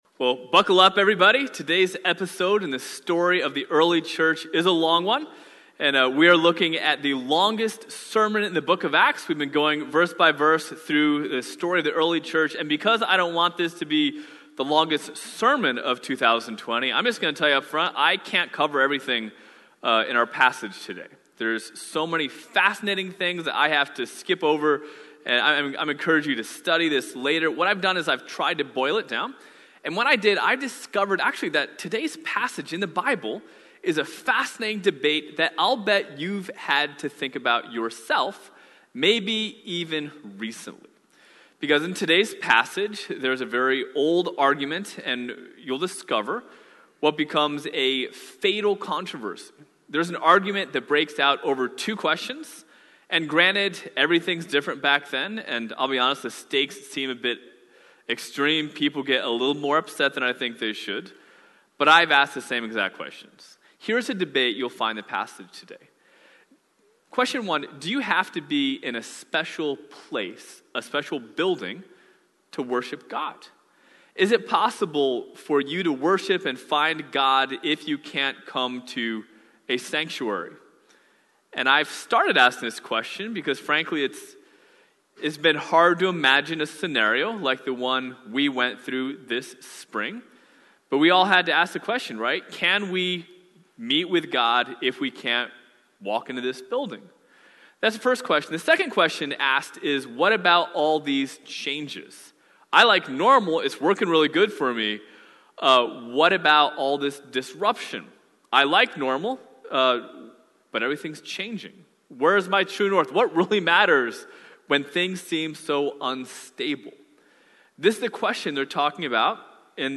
SermonSept30.mp3